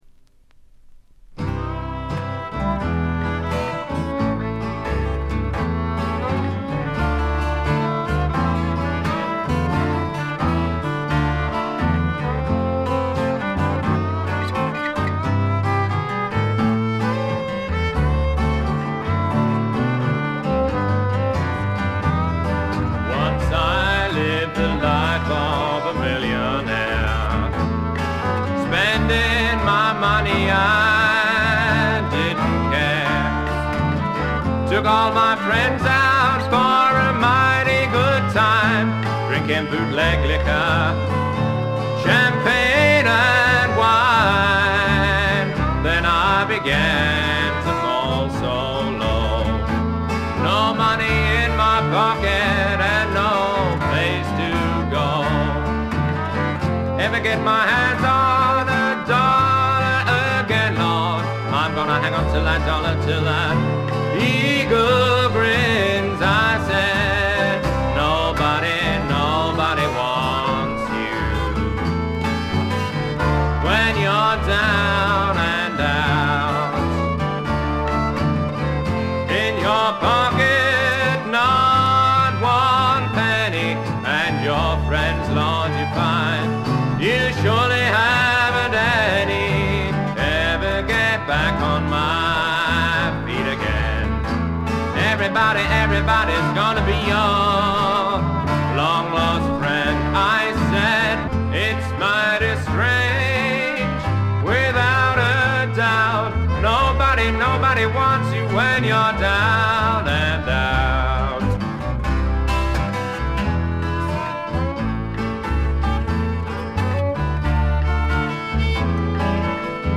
軽微なバックグラウンドノイズのみ。
試聴曲は現品からの取り込み音源です。